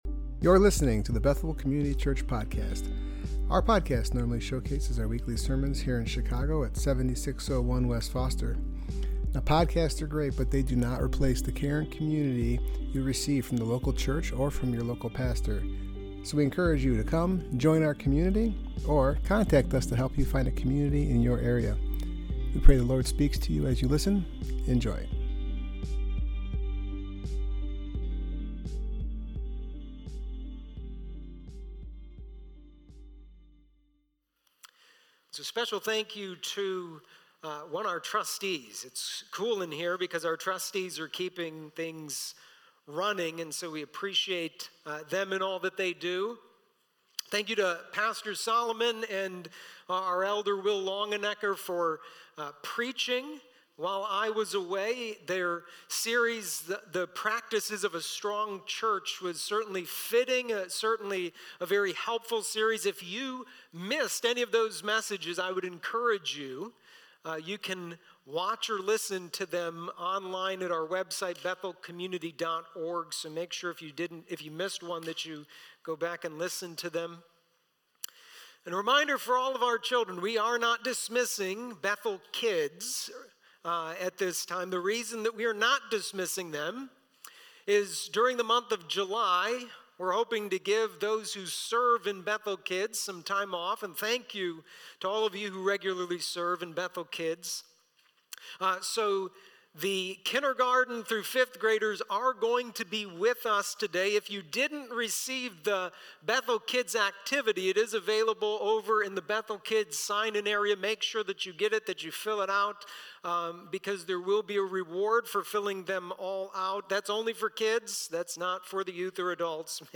Passage: Ephesians 6:1-4 Service Type: Worship Gathering